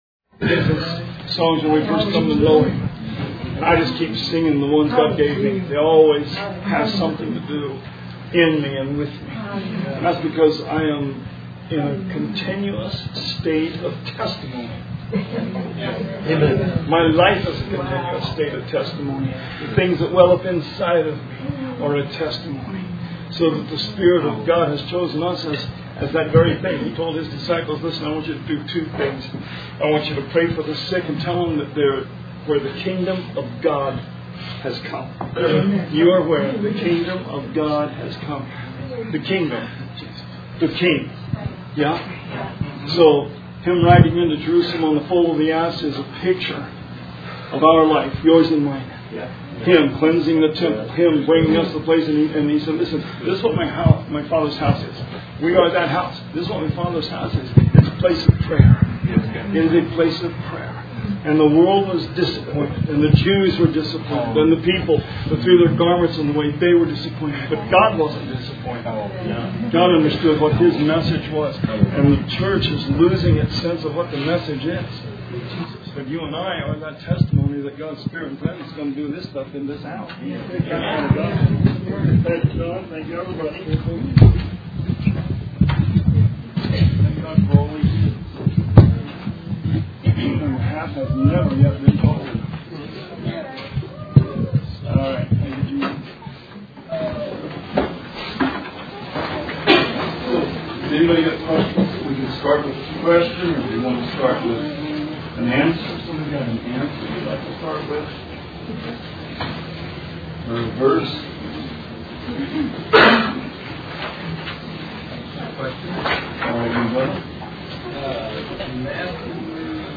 Bible Study 7/24/19